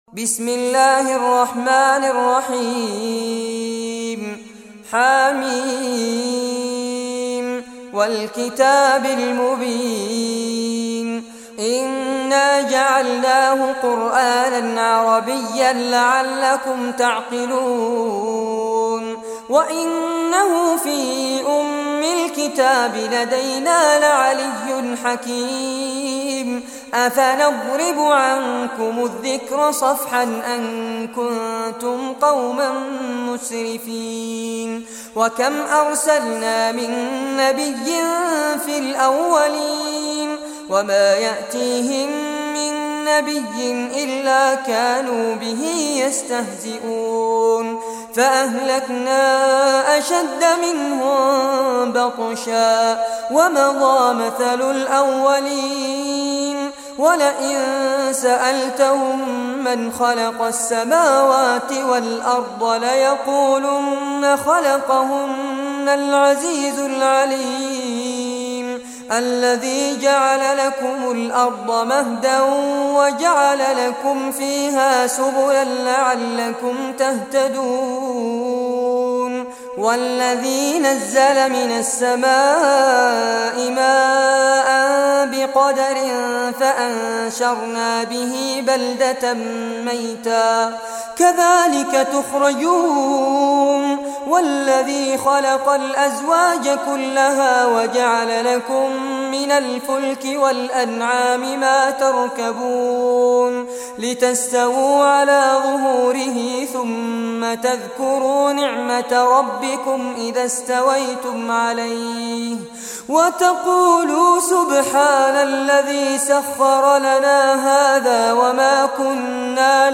Surah Az-Zukhruf Recitation by Fares Abbad
Surah Az-Zukhruf, listen or play online mp3 tilawat / recitation in Arabic in the beautiful voice of Sheikh Fares Abbad.